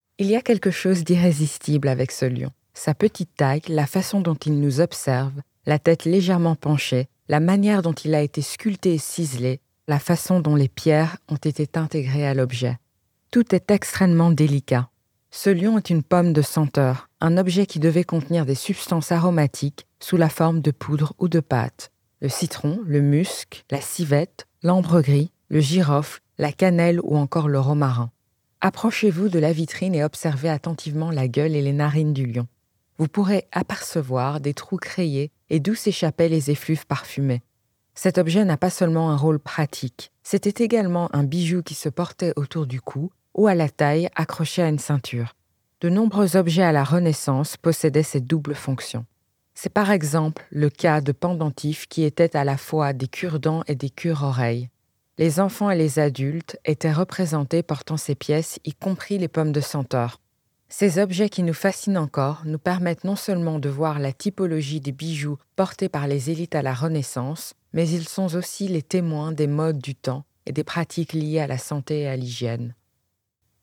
Curator's Reflection (French)